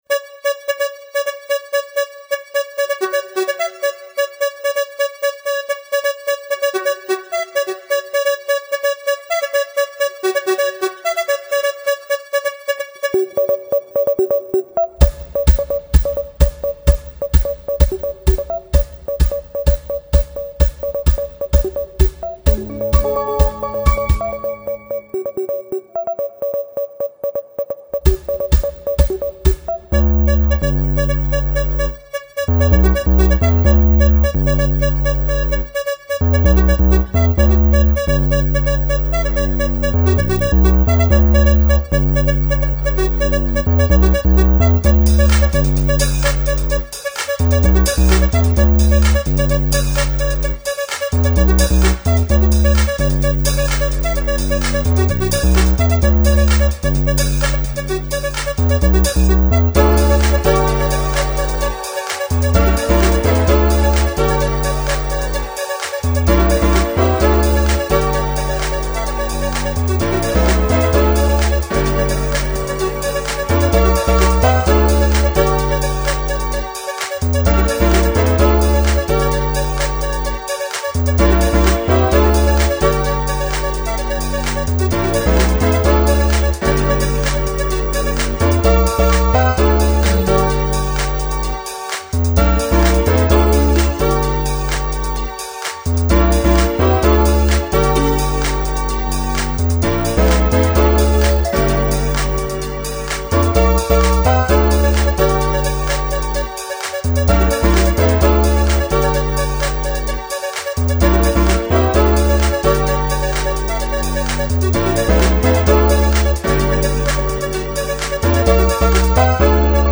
dance/electronic
Techno
Leftfield/noise
Drum & bass